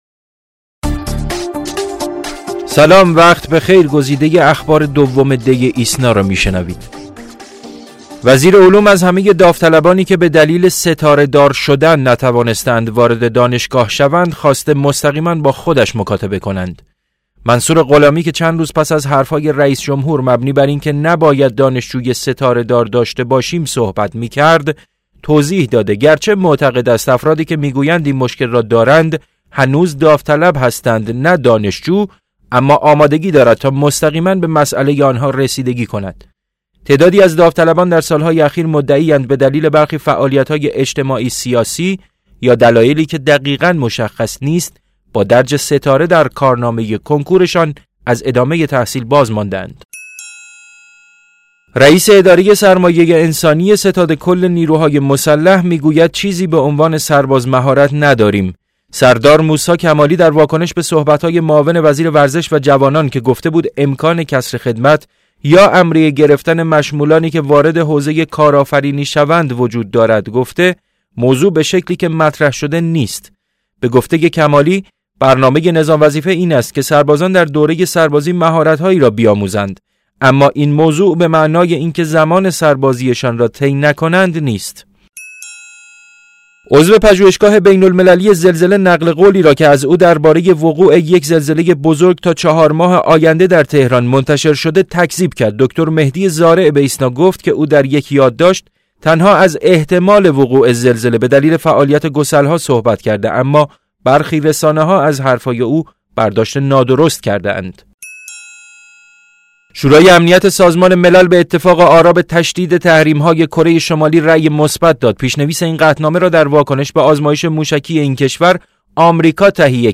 صوت / بسته خبری ۲ دی ۹۶